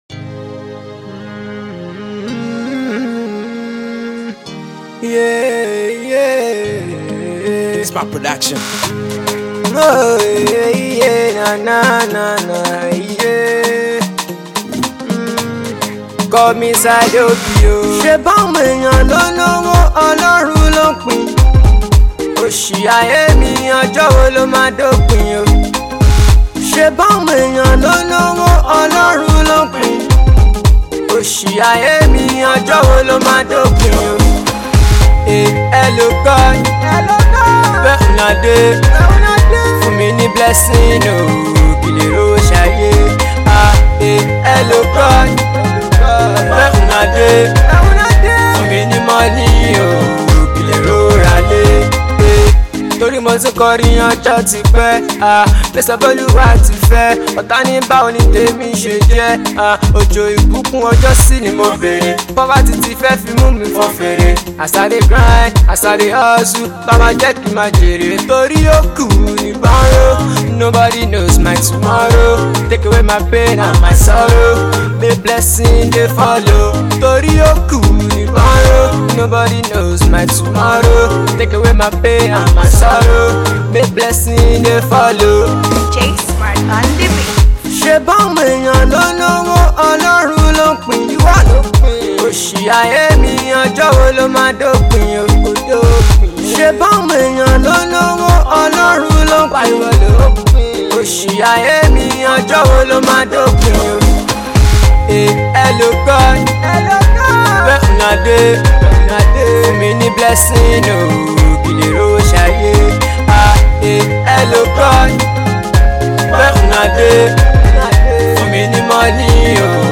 A Nigerian singer